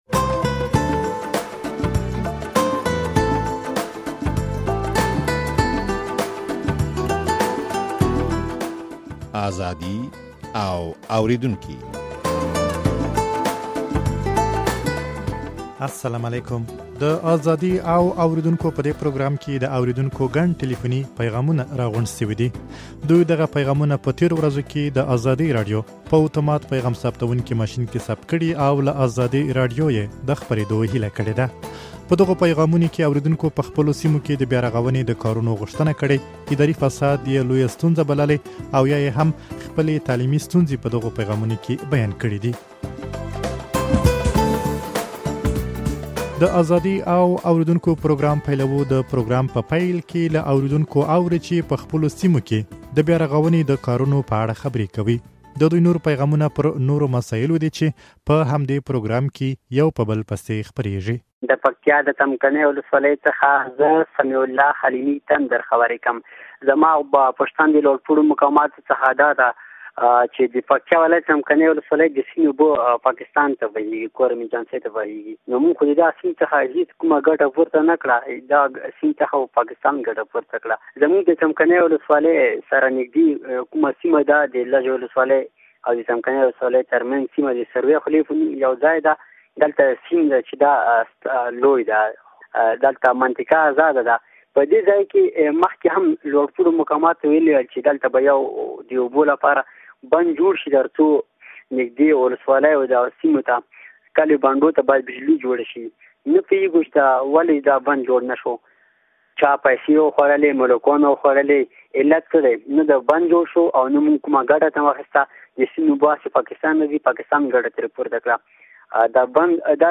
دوى دغه پيغامونه په تېرو ورځو کې د ازادۍ راډيو په اتومات پيغام ثبتوونکي ماشين کې ثبت کړي دي.